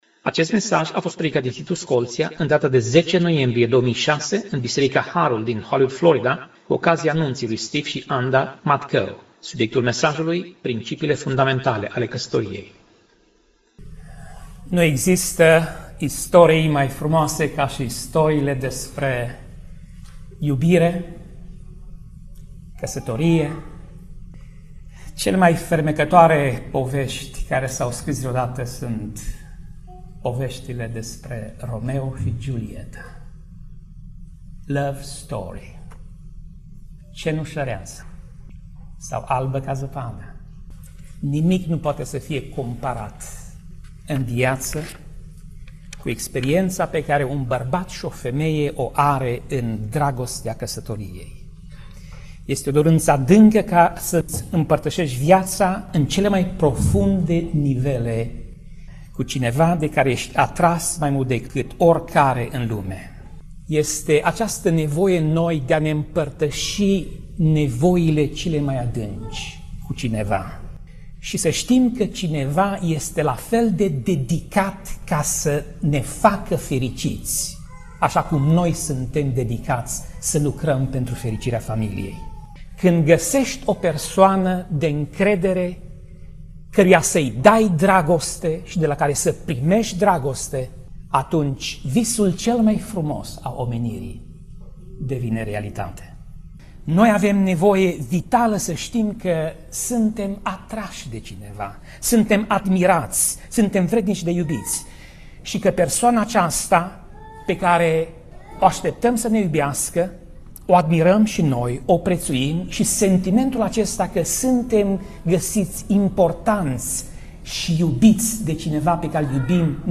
Pasaj Biblie: Geneza 2:18 - Geneza 2:25 Tip Mesaj: Predica